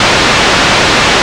77 NOISE.wav